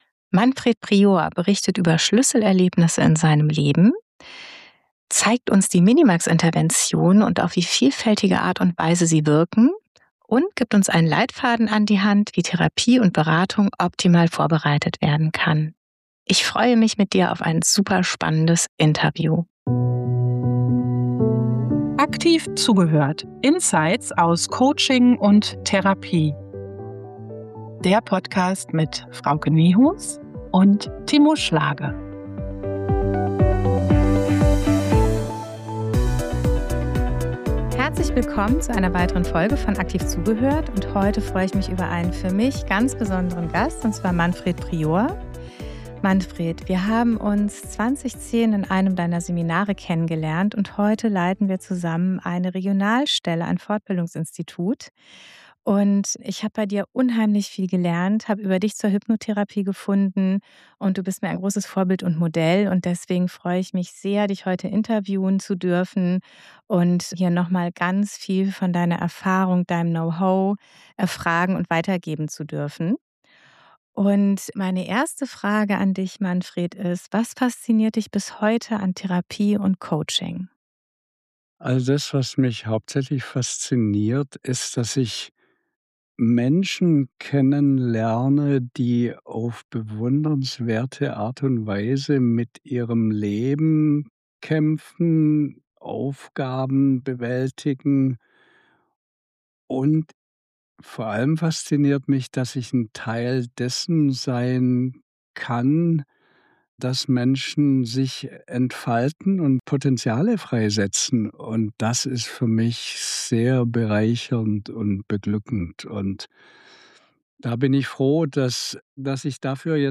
Aktiv Zuhören – Interview